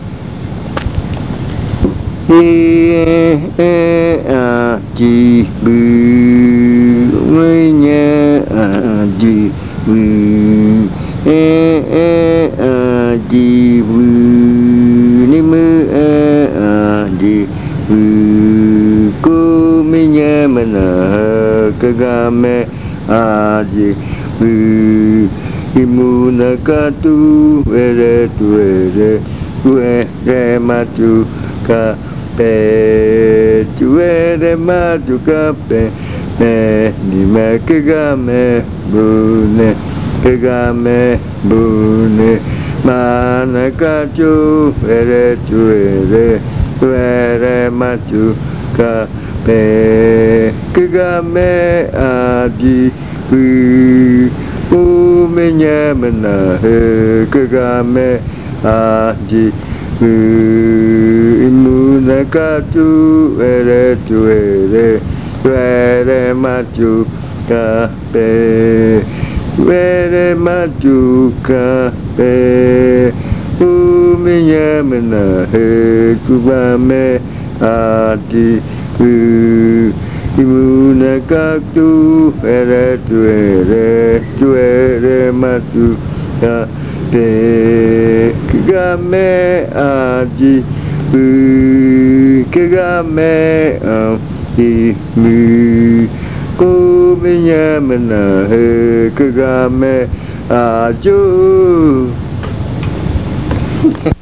Leticia, Amazonas
Esta canción se canta en la madrugada. Canto que se relaciona con el pájaro carpintero. El cantor canta esta canción como despedida del baile de la maloca, en la madrugada, para ir a su casa.
This chant is sung in the early morning.
The singer sings this chant as a farewell to the maloca dance, in the early morning, to go to his house.